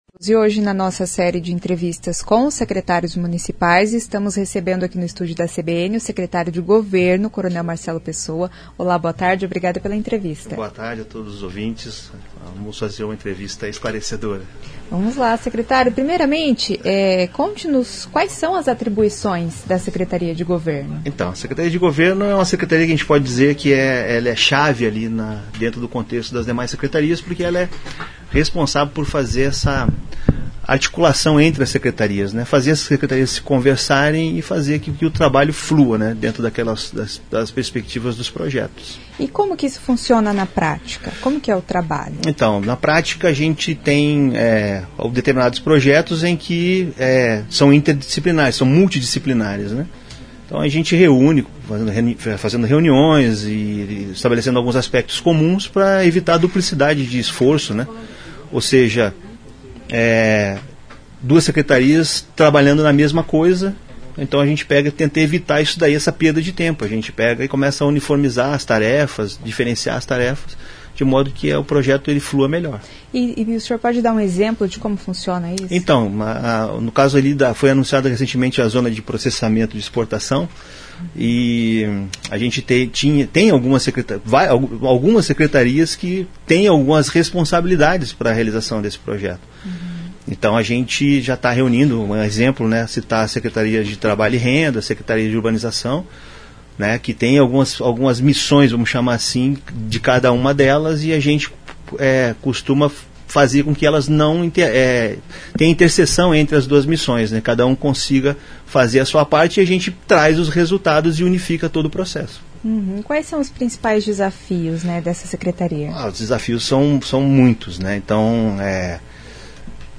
O secretário coronel Marcelo Pessoa explica que um dos objetivos é evitar que as pastas façam trabalho repetido sem saber.